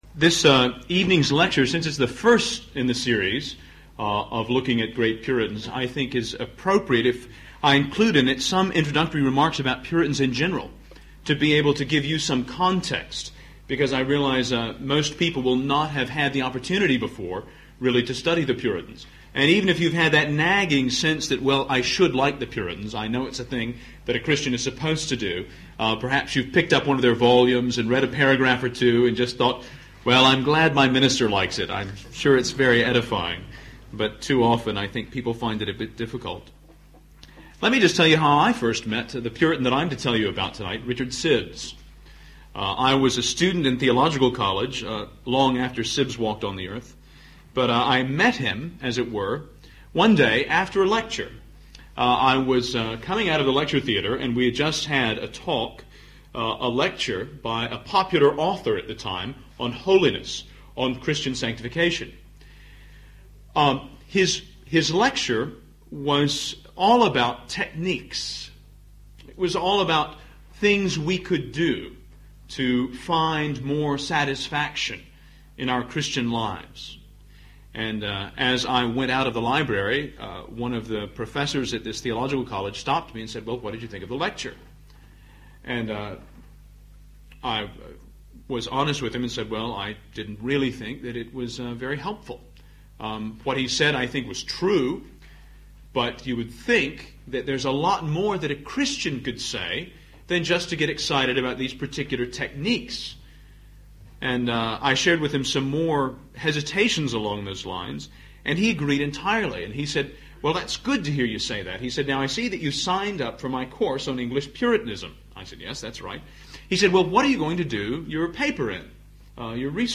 1993 Autumn Lectures
aut_lecture1_1993.mp3